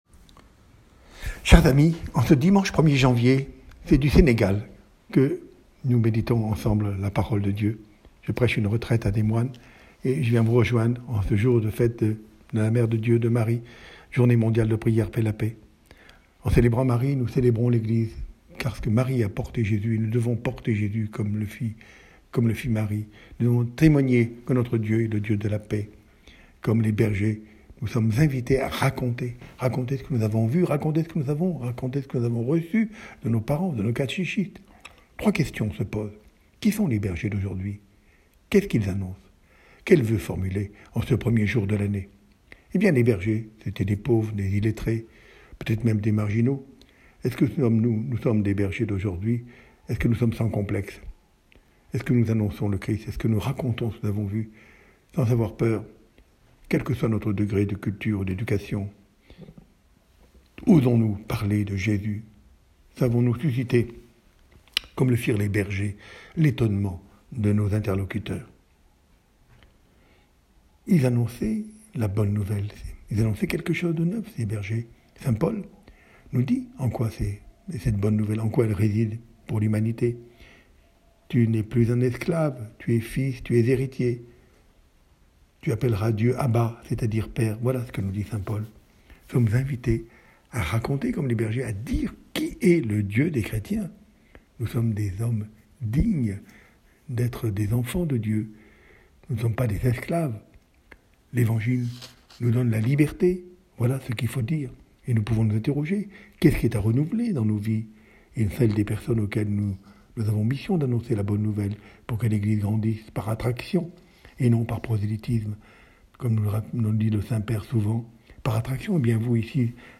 Homélie du 1er janvier par Mgr Colomb :